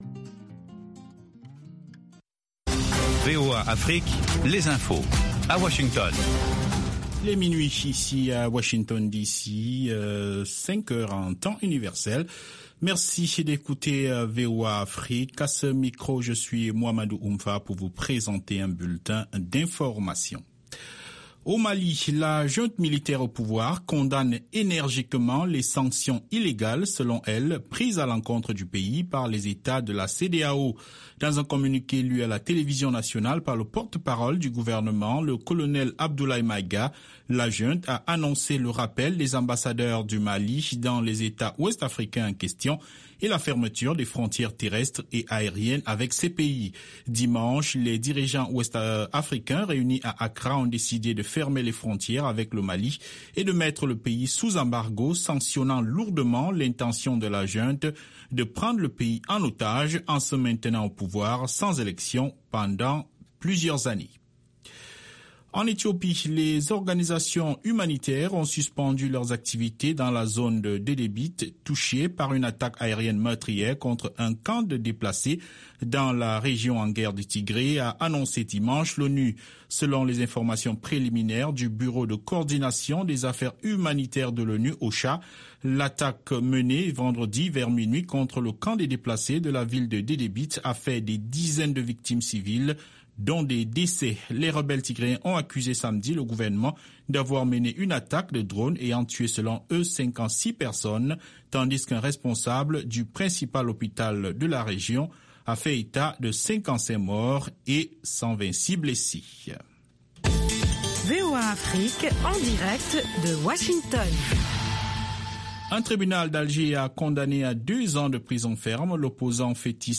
Bulletin
5 min News French